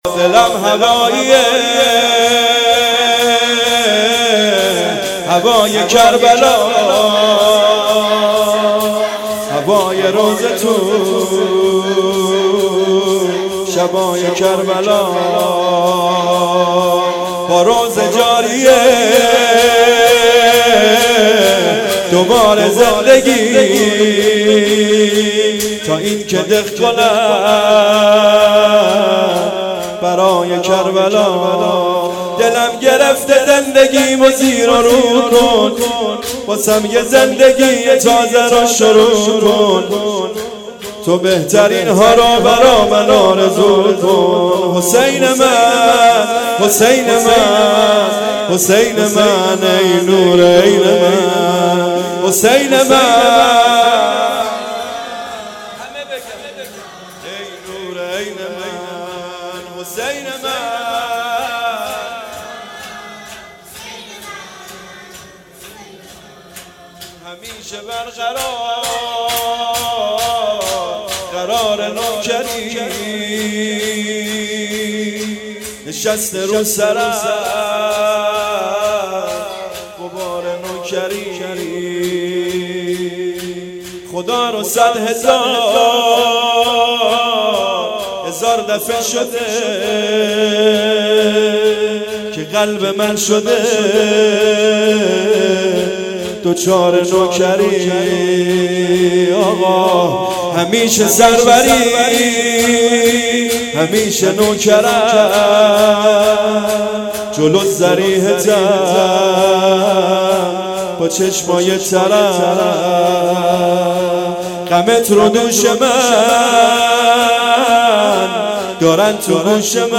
شهادت امام صادق علیه السلام-شب سوم